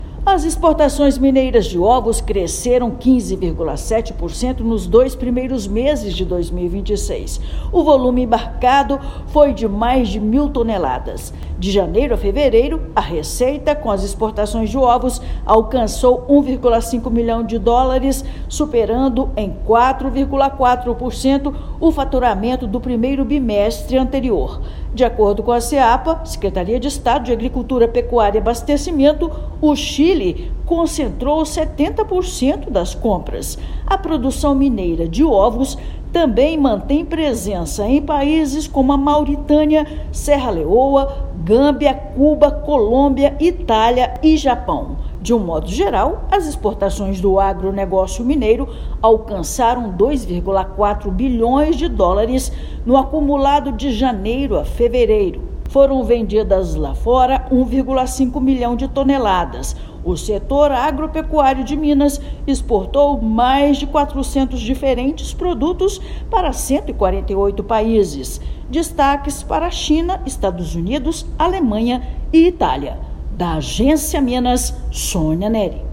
Desempenho do setor avícola foi puxado pela abertura do mercado chileno para ovos brasileiros, mas produção também tem chegado à África, Europa e Ásia. Ouça matéria de rádio.]